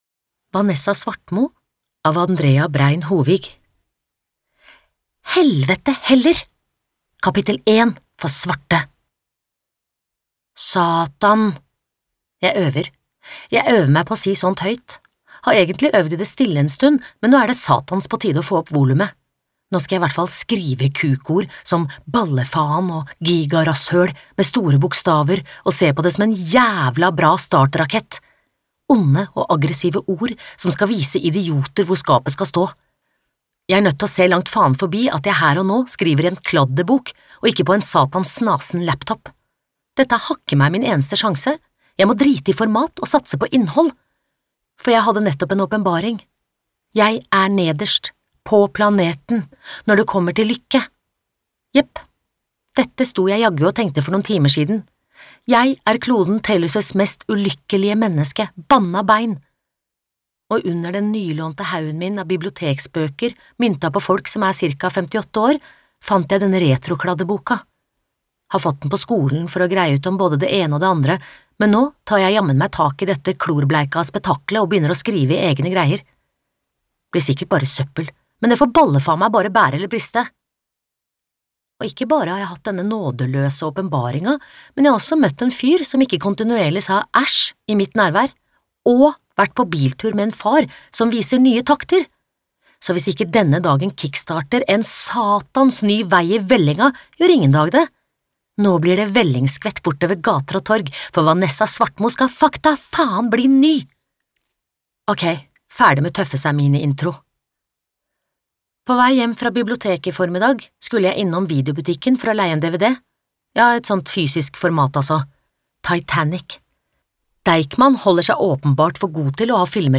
Norwegian female voice talent.